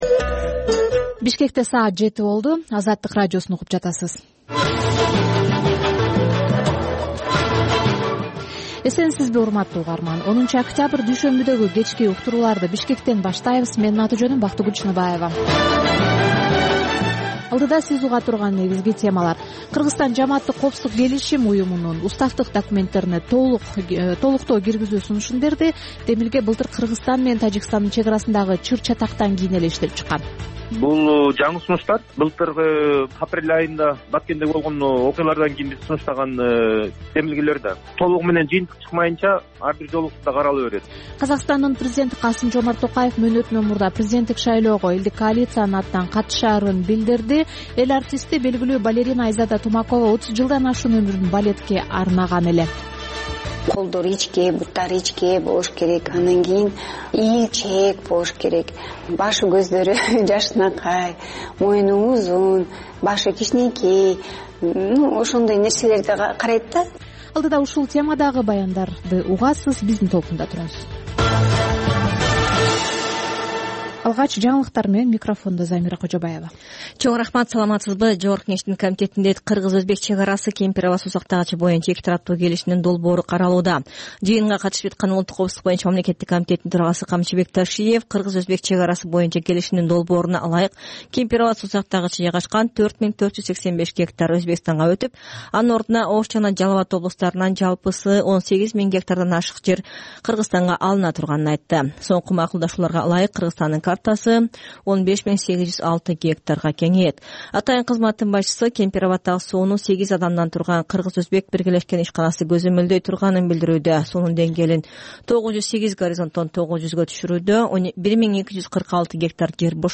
Бул үналгы берүү ар күнү Бишкек убакыты боюнча саат 19:00дан 20:00га чейин обого түз чыгат.